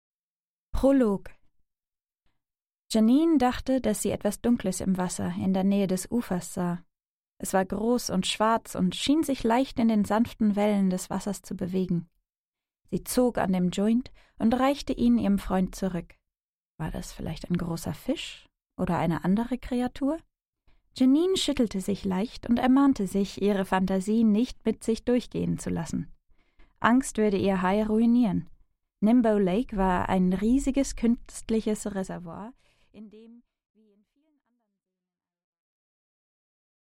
Aудиокнига Ersehnt